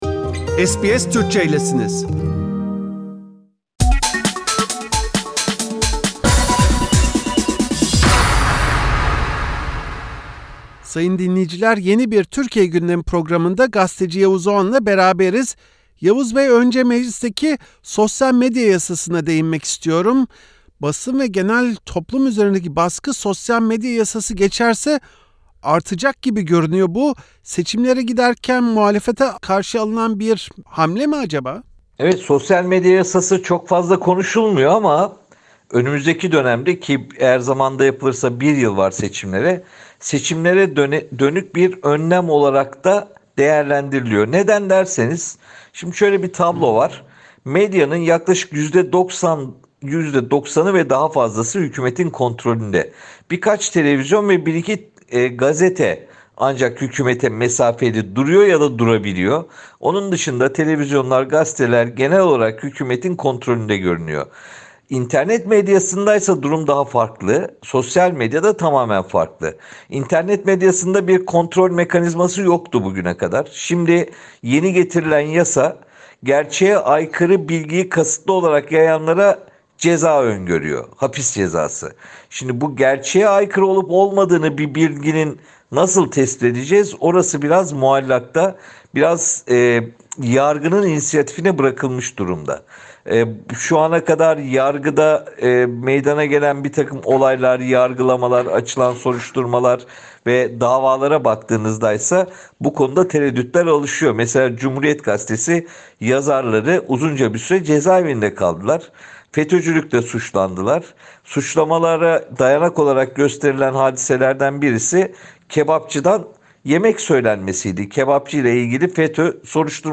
Gazeteci Yavuz Oğhan, SBS Türkçe’ye verdiği röportajda şu anda TBMM’de görüşülen sosyal medya yasasıyla muhalefetin sesinin daha da kesileceğini söylüyor.